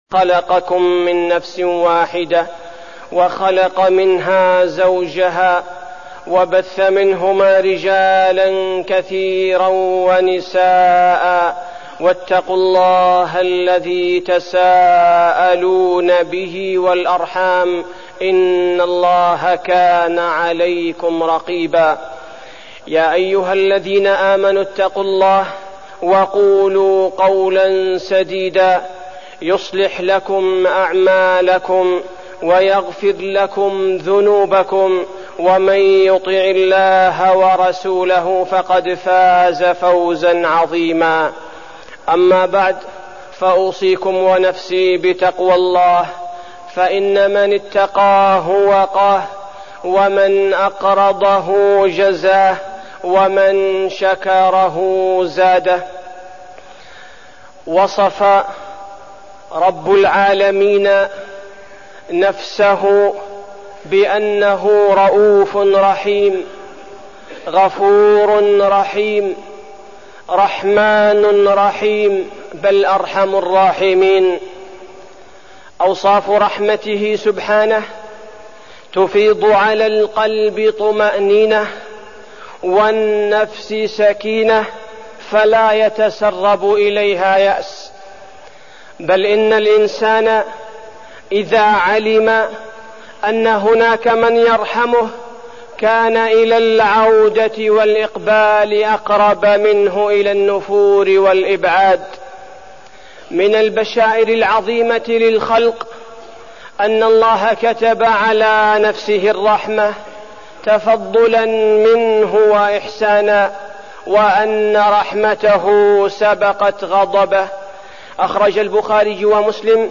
تاريخ النشر ١١ صفر ١٤١٩ هـ المكان: المسجد النبوي الشيخ: فضيلة الشيخ عبدالباري الثبيتي فضيلة الشيخ عبدالباري الثبيتي صور من رحمة الله